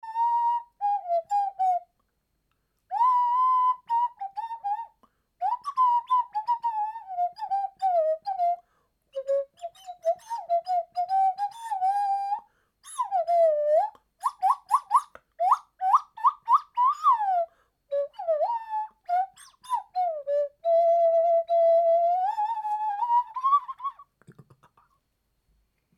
FLUTE NASALE "MASK"3D
Grand modèle de flute à nez, très fun et agréable à utiliser. Elle est fabriquée par impression 3D. Le mode d emploi est simple: placez cette « flute » devant votre nez, soufflez (par le nez!) et modulez le son avec la bouche.